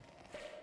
HeadInflatePOP 2.0.wav